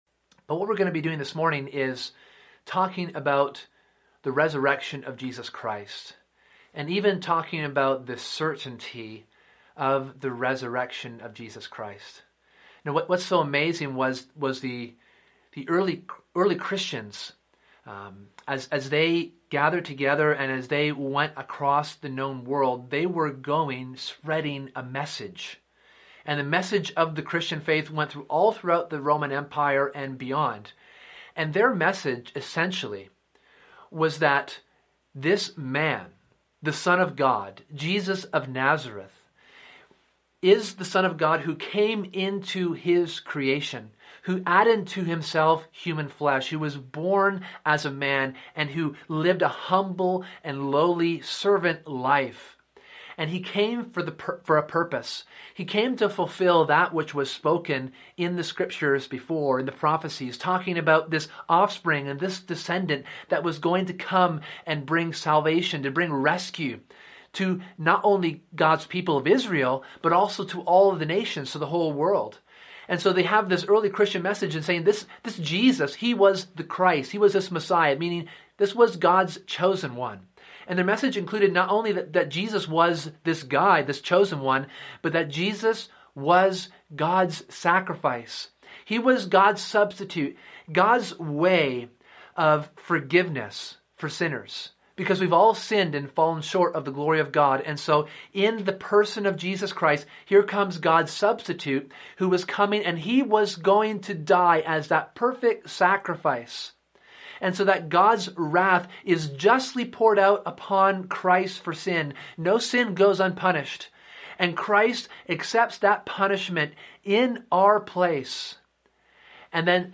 In this sermon we consider the reality of the resurrection of Jesus. What we see is that the reality of the resurrection is not only a vindication of Jesus' life and ministry, it is a guarantee of coming judgment to all people everywhere.